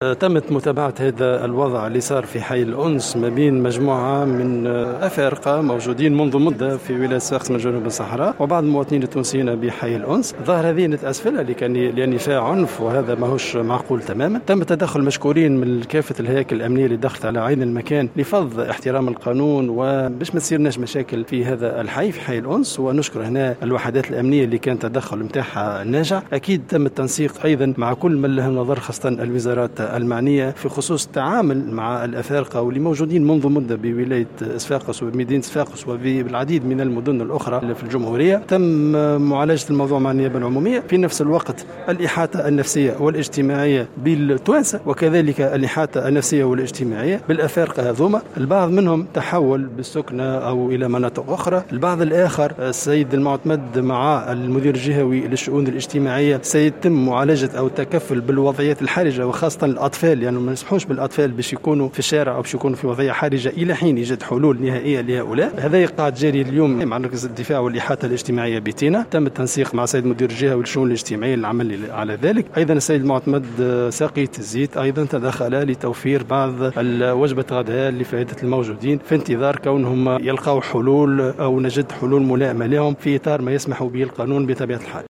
أكد والي صفاقس أنيس الوسلاتي في تصريح لمراسل "الجوهرة أف أم" اليوم الثلاثاء أنه تم التدخّل من كافة الهياكل الأمنية لفرض احترام القانون وتفادي العنف بحي الأنس وذلك على اثر الأحداث التي عاشتها المنطقة مساء امس تمثلت في مواجهات بين مهاجرين وسكان المنطقة.